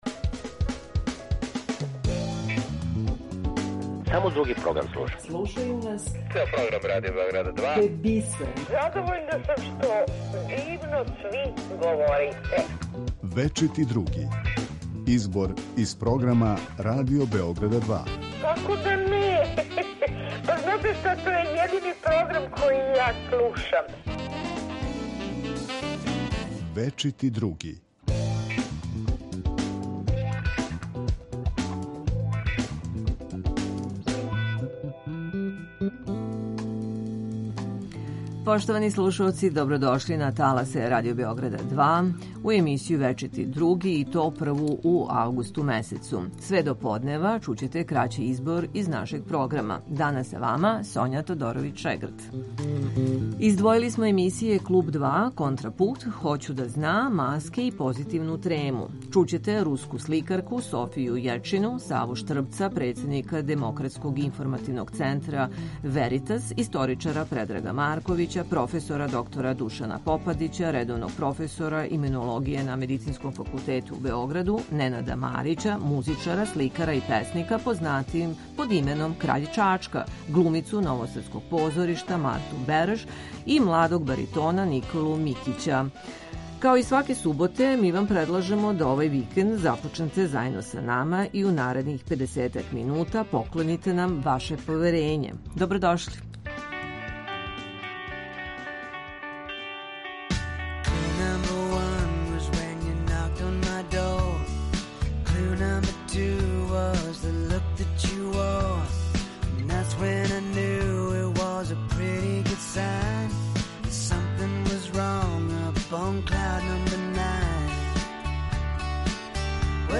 У емисији Вечити Други чућете избор из програма Радио Београда 2.